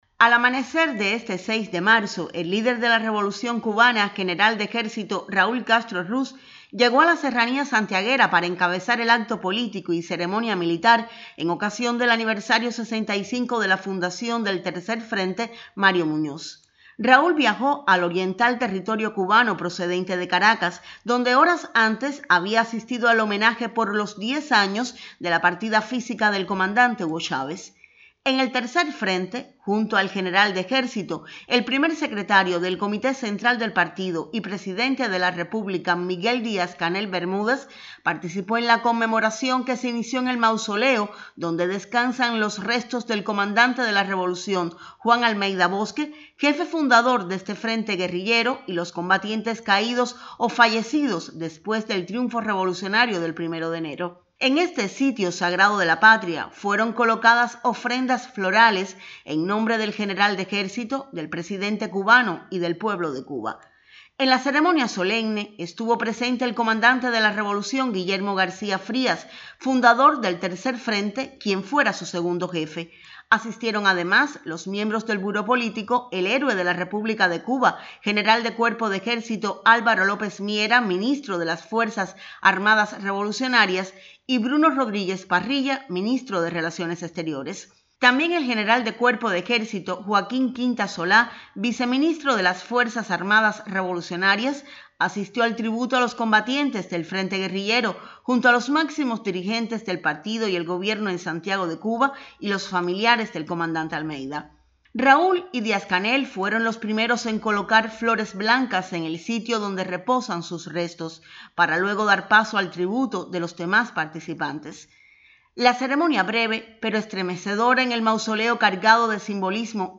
El líder de la Revolución cubana, General de Ejército Raúl Castro Ruz, y el Primer Secretario del Partido y Presidente de la República, Miguel Díaz-Canel Bermúdez, encabezaron el acto político y ceremonia militar por el aniversario 65 de la fundación del Tercer Frente Mario Muñoz